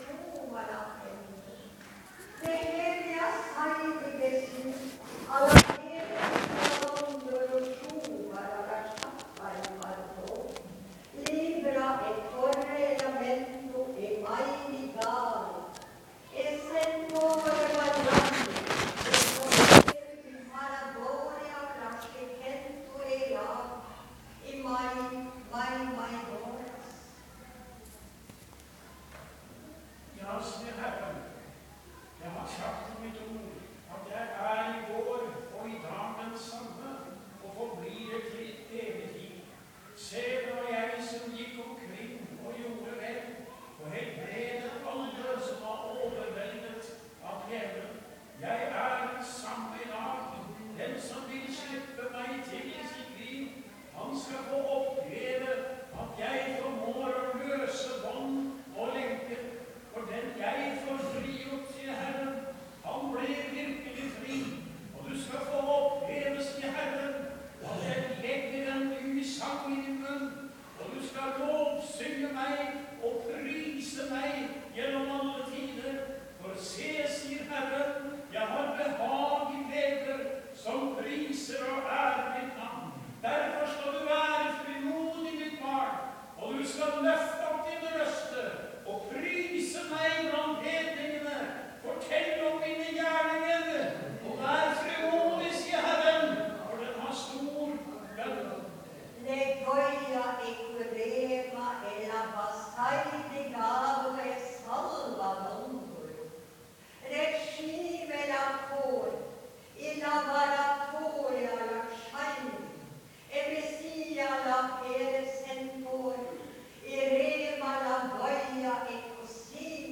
Han vil frelse menneske, sette dei fri og gjere dei til nye skapningar. Møte i Maranatha 18.8.2013.
Tungetale